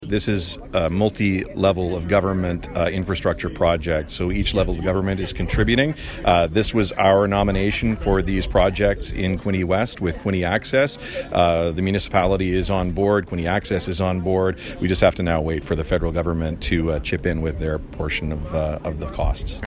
Smith explains how all three levels of governments are chipping in to make this project work.
Todd-Smith-on-Quinte-West-transit-project.mp3